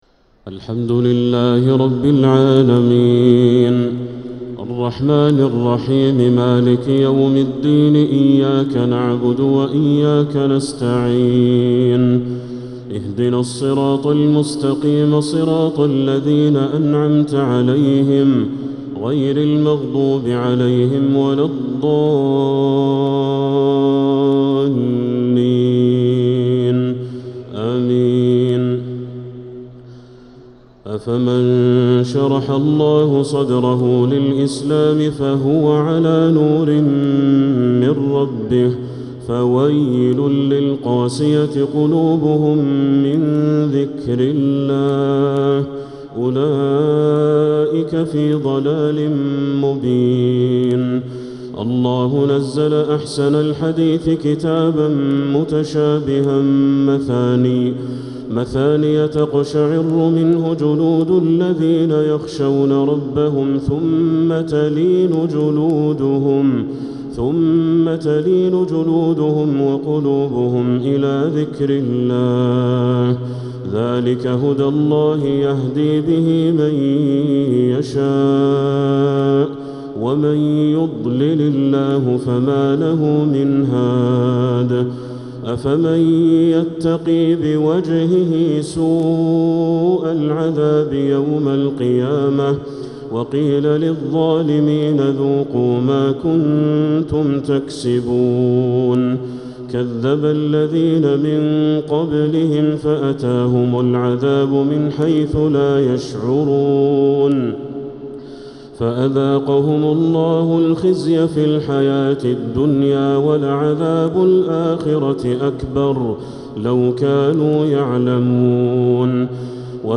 تهجد ليلة 25 رمضان 1446 من سورة الزمر (22-75) وغافر (1-33) | Tahajjud 25th night Ramadan 1446H Surah Az-Zumar and Ghafir > تراويح الحرم المكي عام 1446 🕋 > التراويح - تلاوات الحرمين